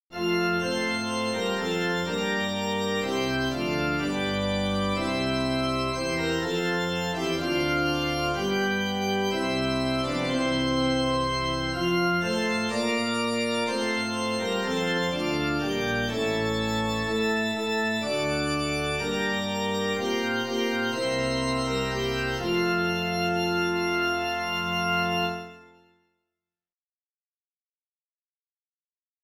Mostly I wanted something festive and cheerful which would take some inspiration from Dirksen's festive melody.
The structure is actually closer to a chaconne with an 8-bar phrase which is then repeated, embellished, etc. (Pachelbel wrote lots of chaconnes as well.)
And this is, more or less, what I played this morning (recording is feeble cellphone recording from before church).